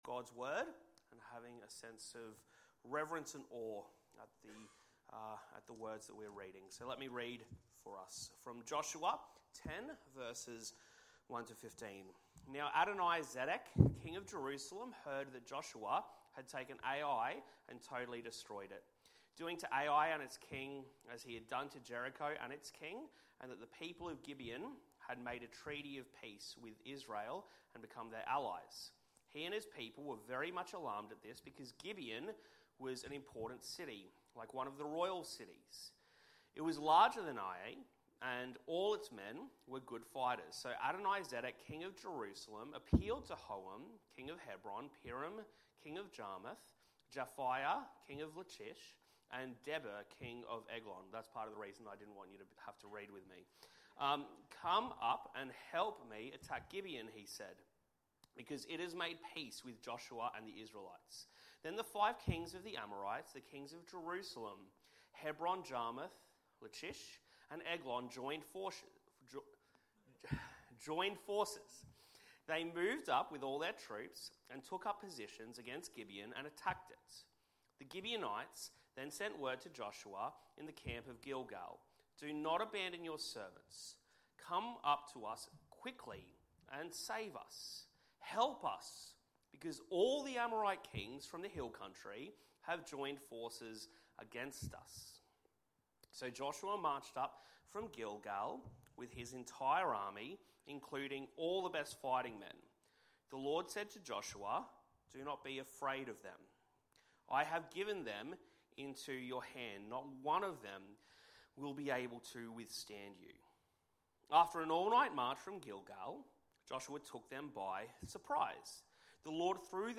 Sermons | Church At The Gabba